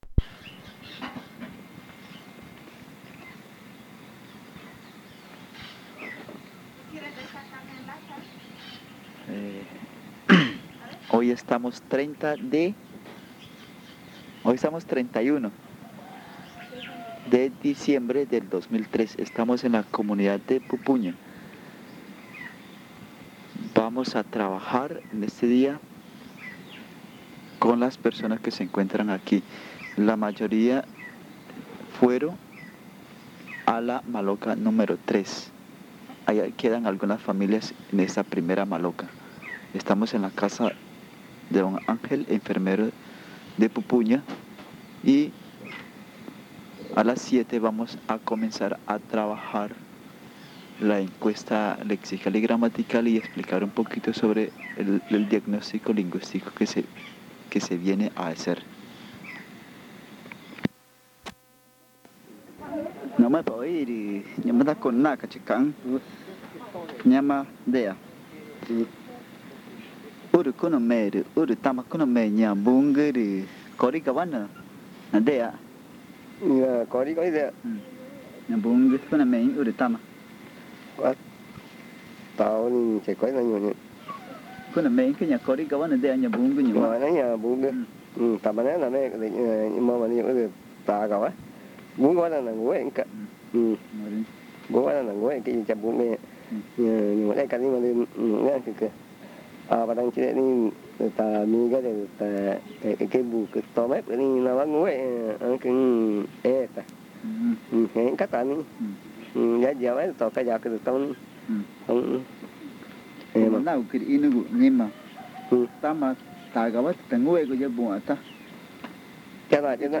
Encuesta léxica y gramatical grabada en casete en la Comunidad Indígena de Pupuña (Amazonas, Colombia)
El audio contiene los lados A y B.